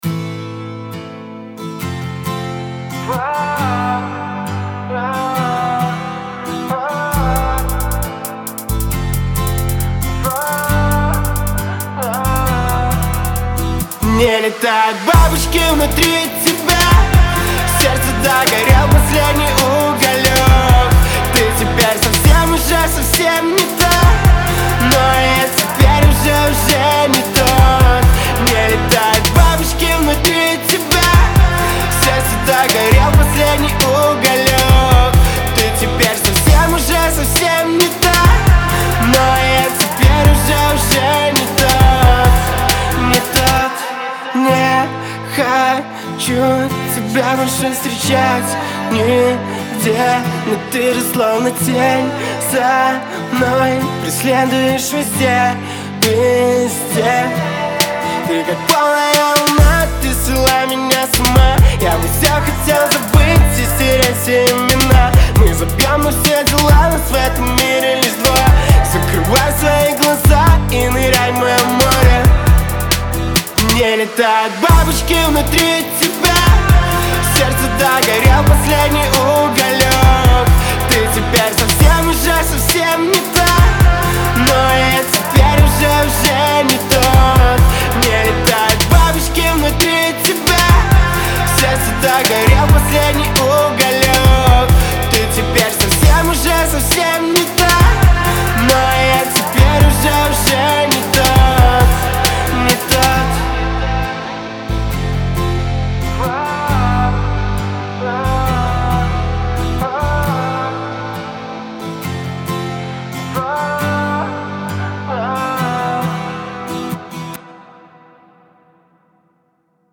Настроение песни светлое, но с нотками ностальгии.